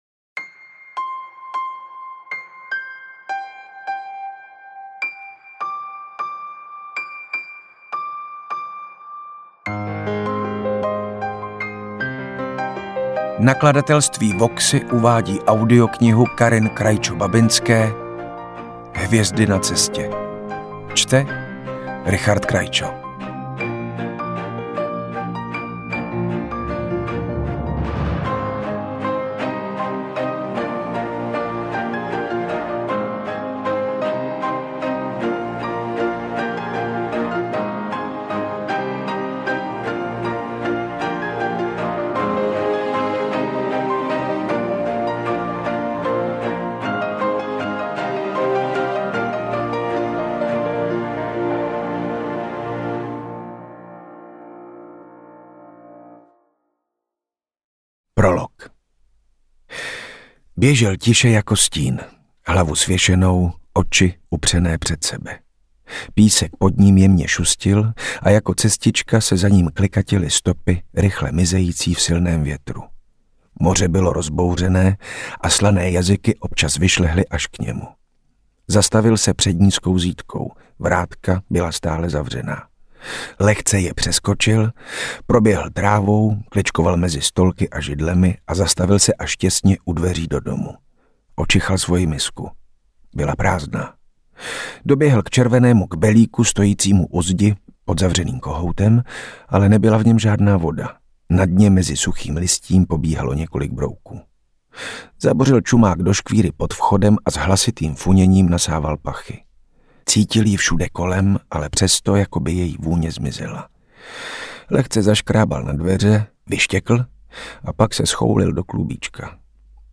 3,5* Knížku jsem četla ušima a musím konstatovat, že je to jedna z mála knih, kde interpret (manžel autorky - Richard Krajčo) zcela převálcovat obsah.
AudioKniha ke stažení, 76 x mp3, délka 17 hod. 1 min., velikost 933,4 MB, česky